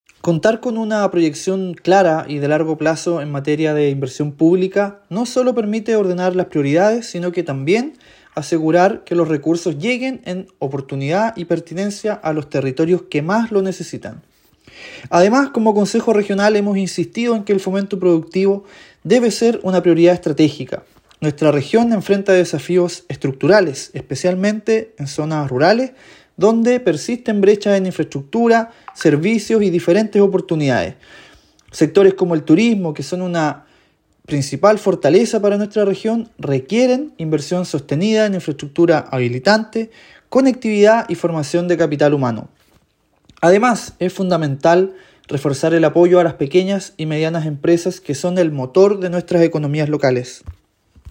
Consejero Regional Arcadio Soto